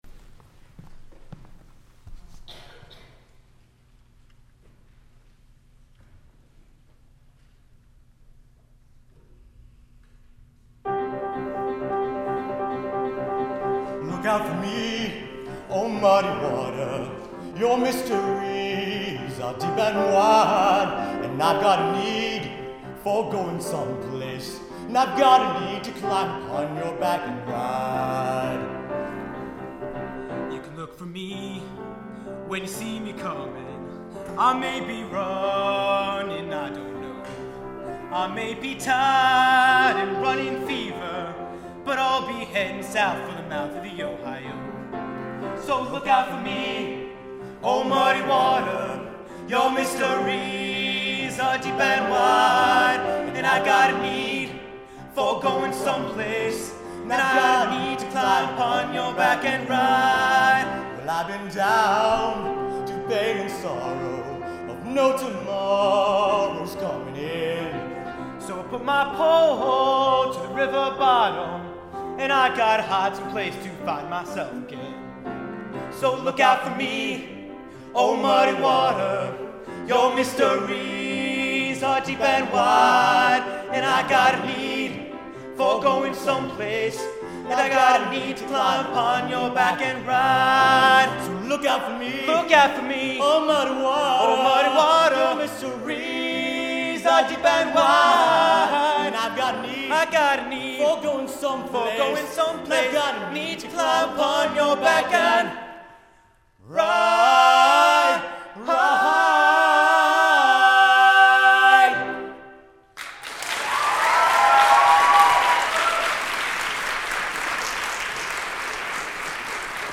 Live on-stage recording